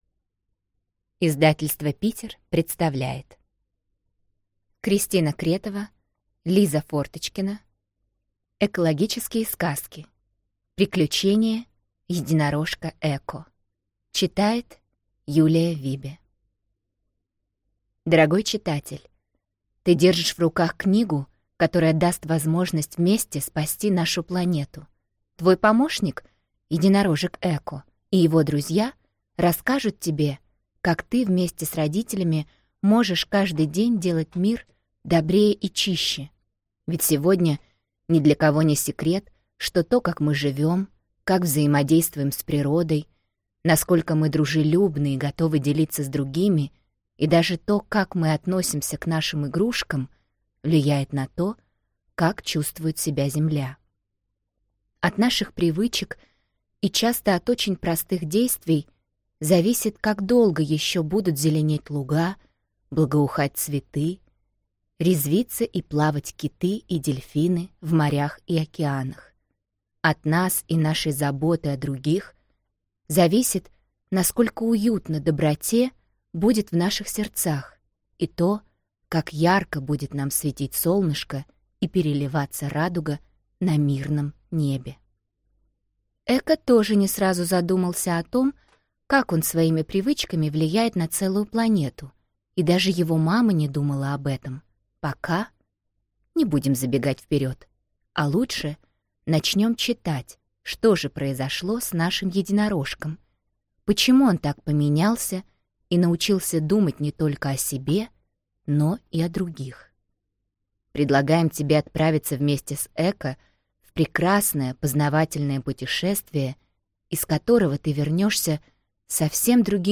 Аудиокнига Приключения Единорожка Эко. Экологические сказки | Библиотека аудиокниг